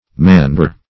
Search Result for " mandore" : The Collaborative International Dictionary of English v.0.48: Mandore \Man"dore\, n. [See Mandolin , and Bandore .]